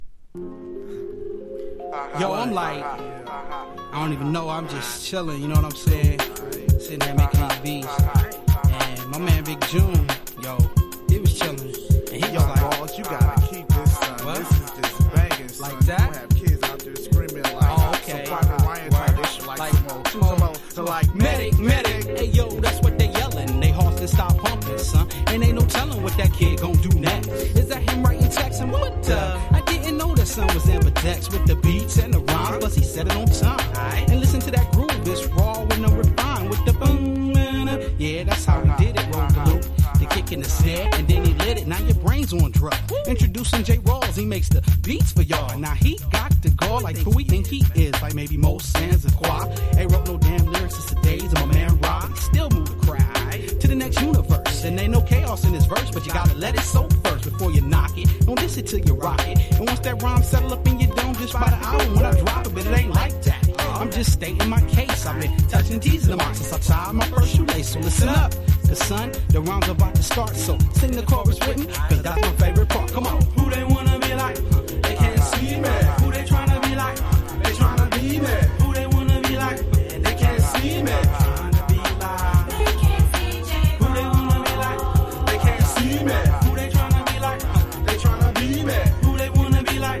程良いジャジーな質感がたまらない1枚。
# JAZZY HIPHOP